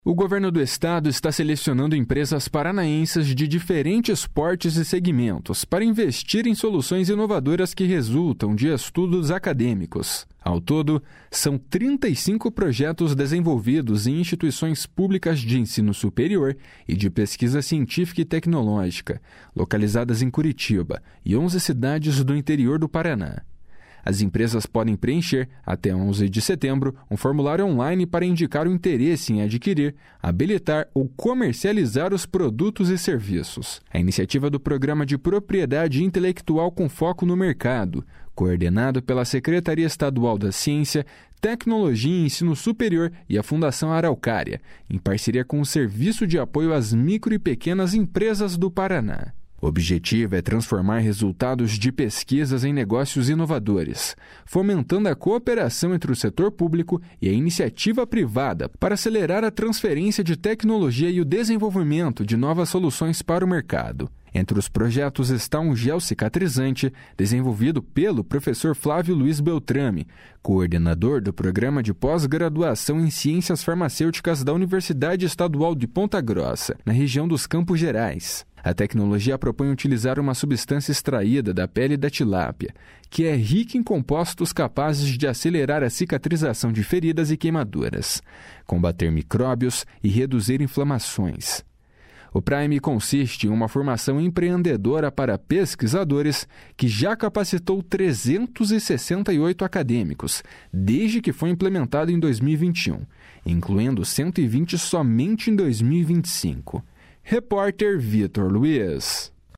Reportagem
Narração